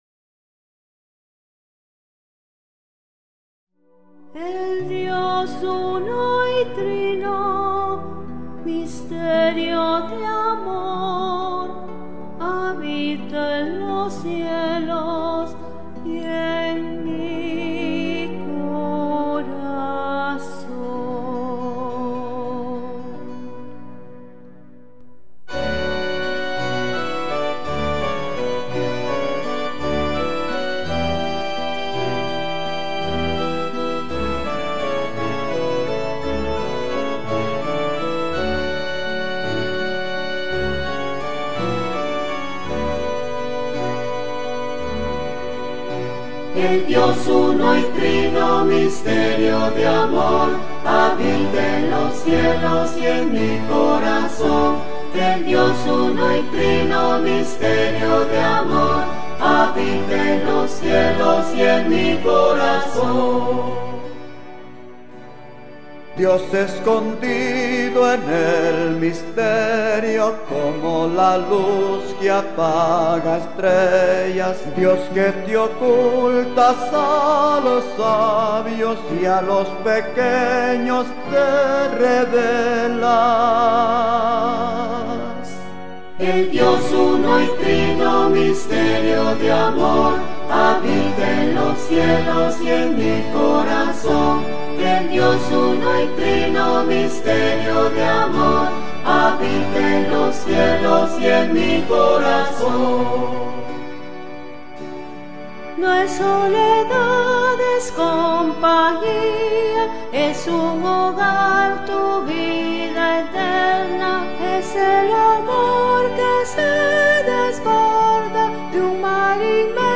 CANTO-A-LA-SANTISIMA-TRINIDAD-EL-DIOS-UNO-Y-TRINO.mp3